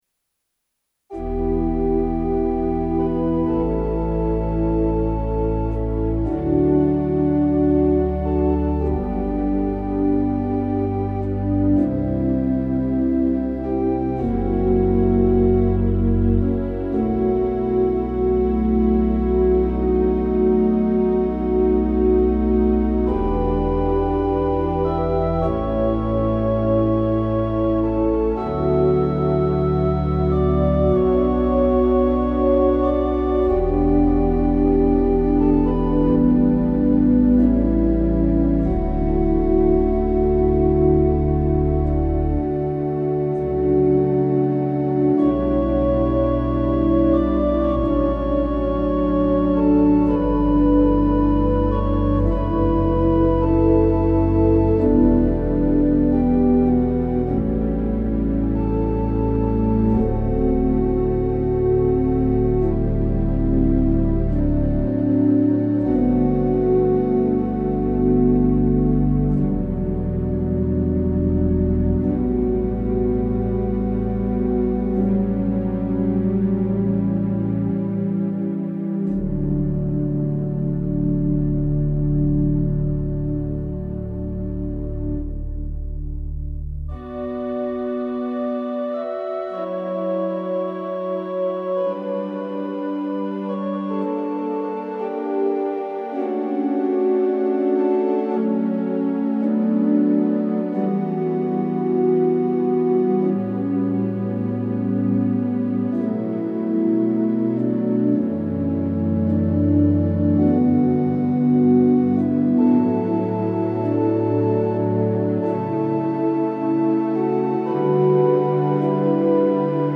Rodgers 205 Hybrid Organ (circa 1978)